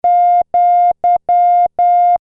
和文符号wabun
和文の文字をクリックすると和文符号が再生されます。